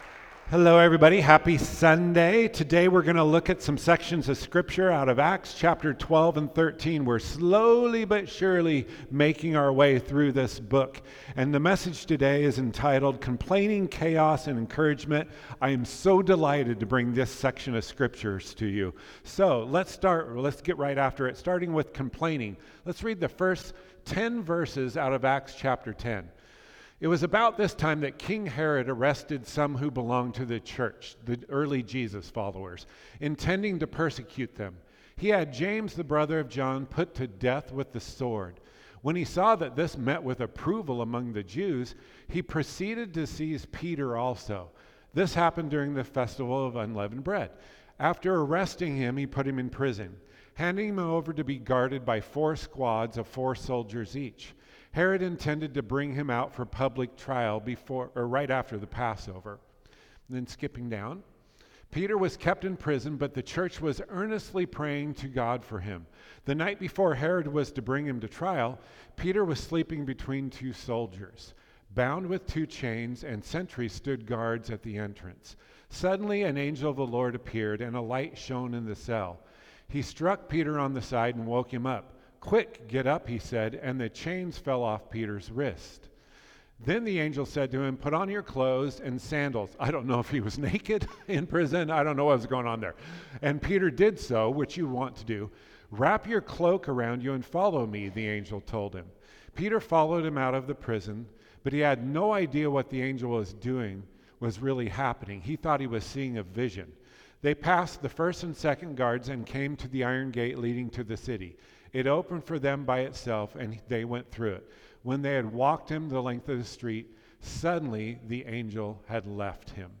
Sermons | Faith Avenue Church